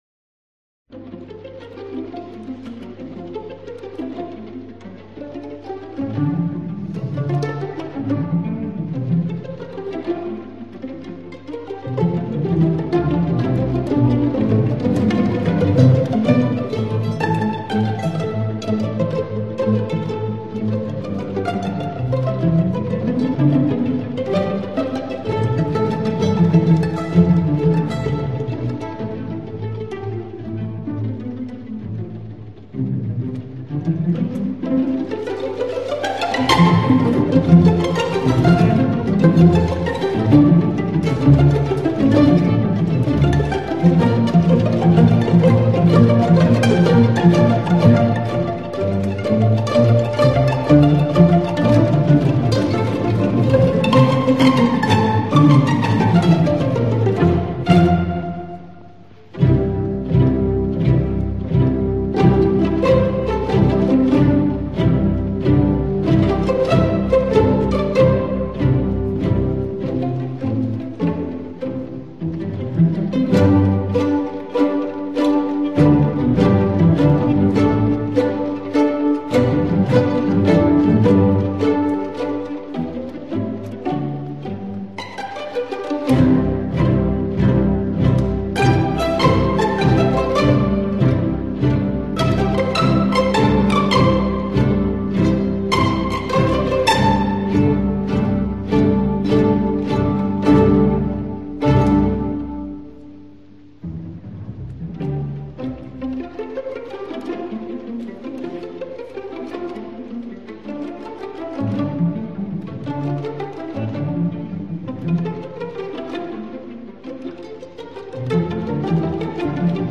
Orchestre de chambre